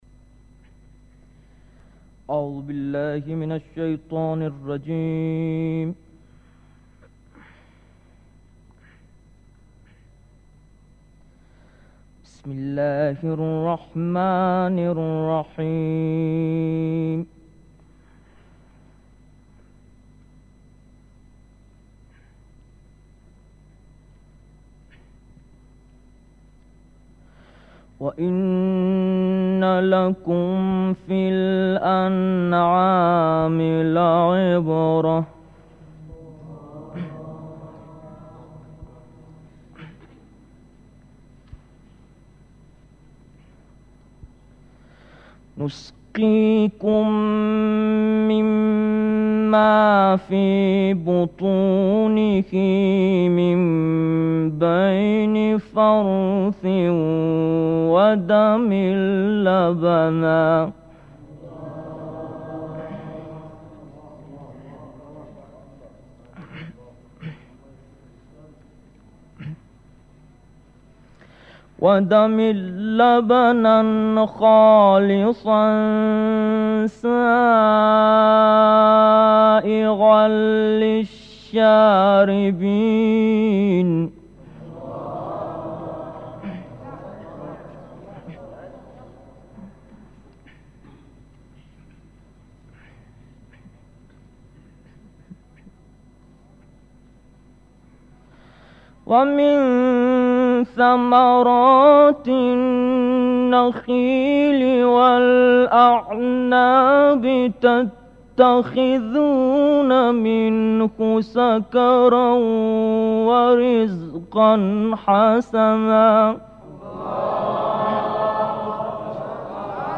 Audio: Recitación de archivo de importante qari iraní